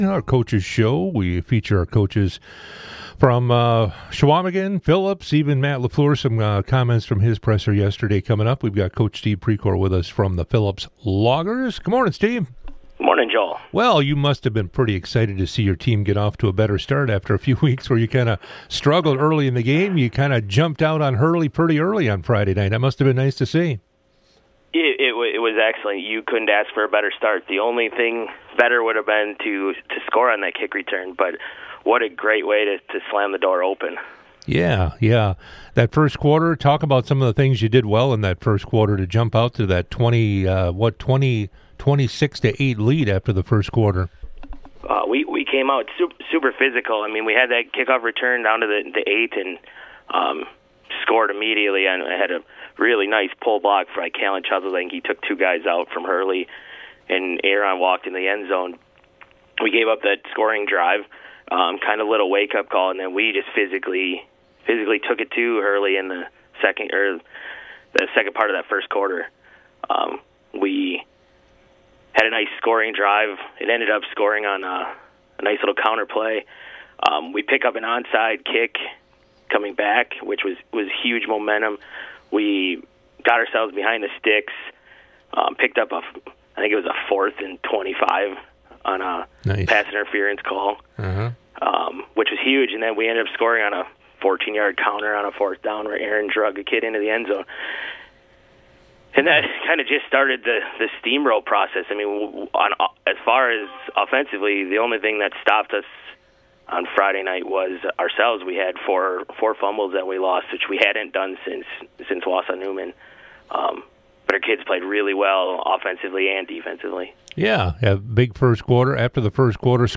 Interviews and special broadcasts from 98Q Country in Park Falls.
98q interviews